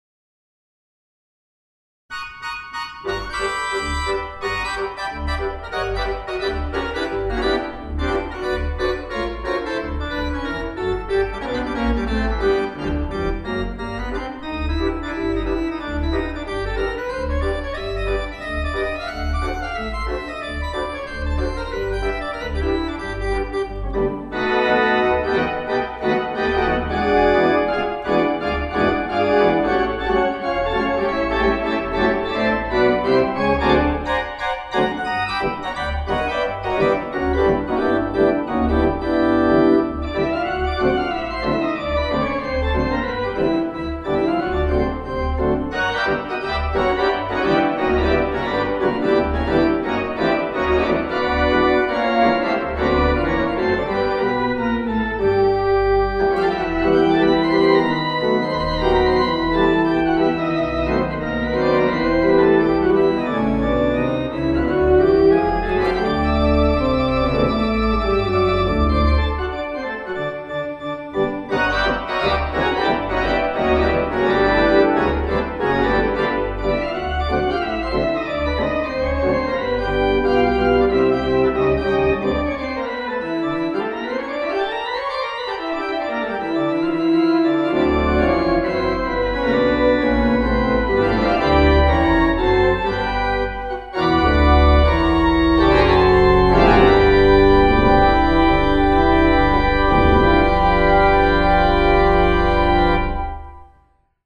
The Palmdale United Methodist church sancuary building.
The console of the Rodgers 755 Digital Church Organ
They had to be sure that everything worked and the tuning held, which it did.